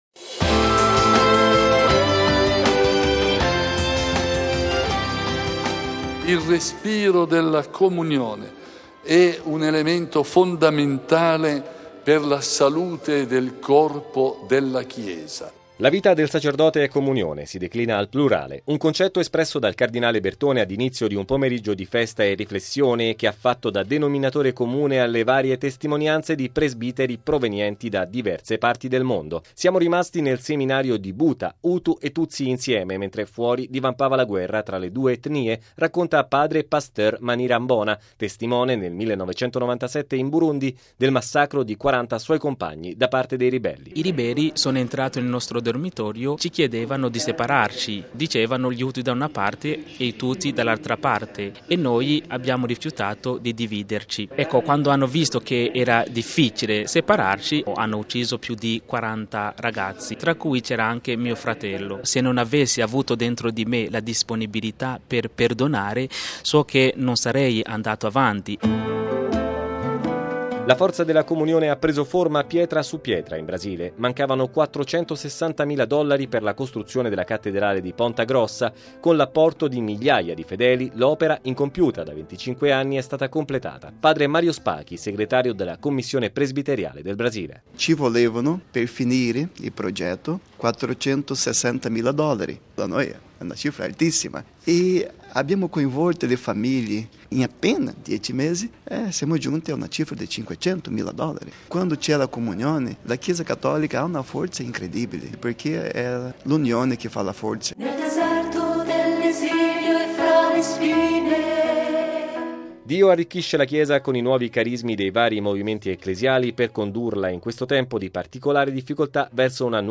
(musica)
(Parole del cardinale Bertone):